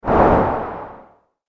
جلوه های صوتی
دانلود صدای باد 59 از ساعد نیوز با لینک مستقیم و کیفیت بالا
برچسب: دانلود آهنگ های افکت صوتی طبیعت و محیط دانلود آلبوم صدای باد از افکت صوتی طبیعت و محیط